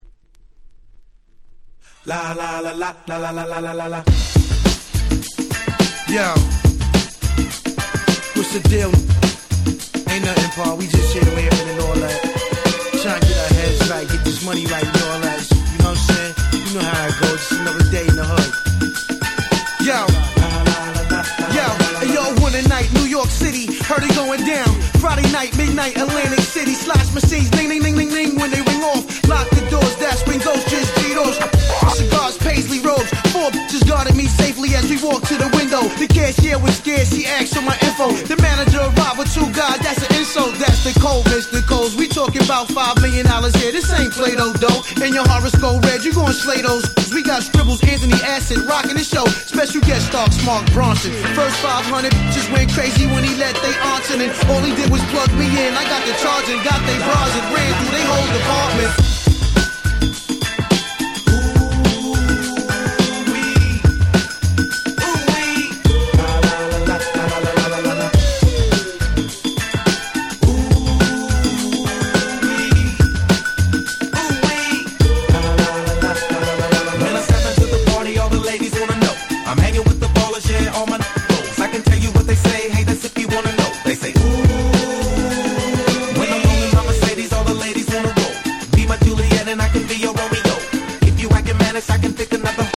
03' Big Hit Hip Hop !!
未だにPlayされると盛り上がる、完璧な00's Hip Hop Classicsです！！
Boom Bap